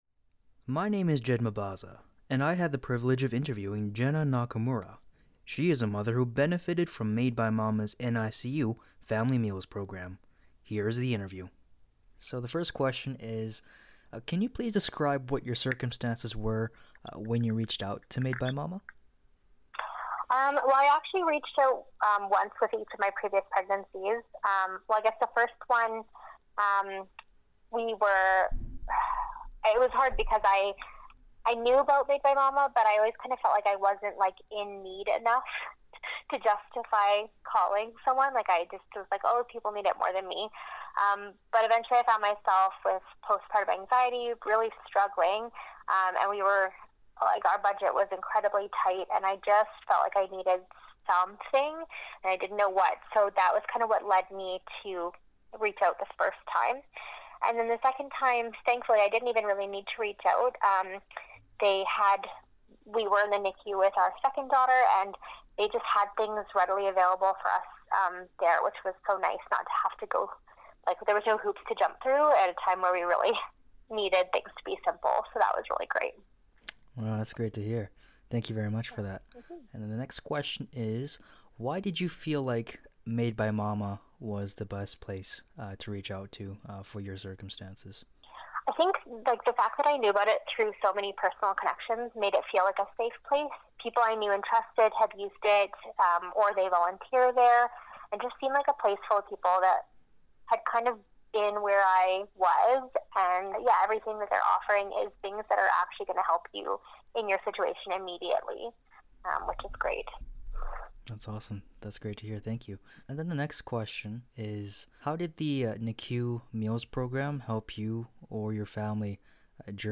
I had the opportunity to interview a mother who benefitted from Made by Momma’s NICU Family Meals Program.